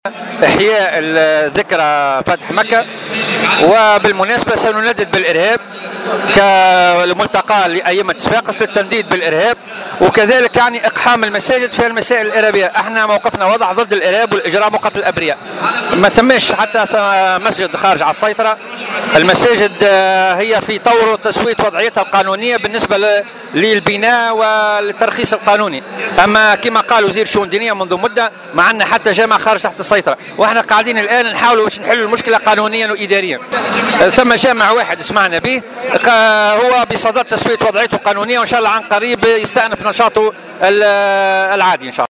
كان ذلك خلال تنظيم تظاهرة الائمة ضد الارهاب